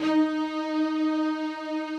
strings_051.wav